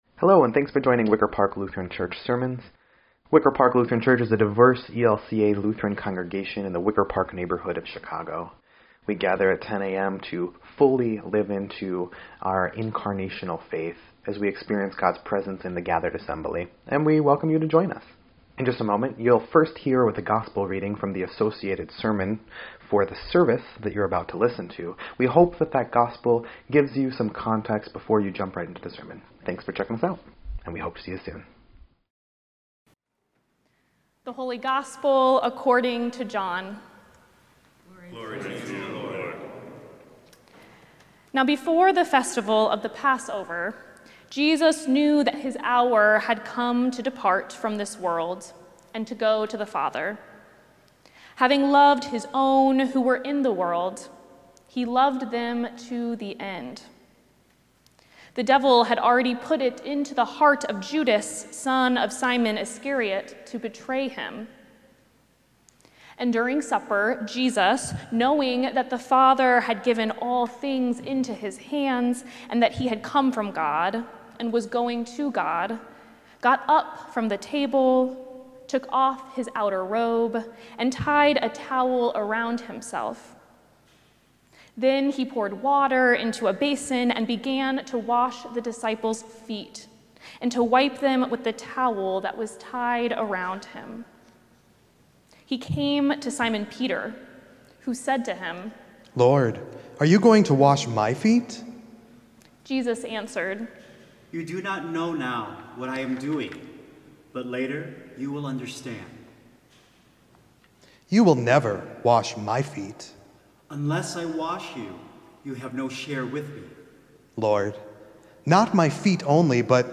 4.2.26-Sermon_EDIT.mp3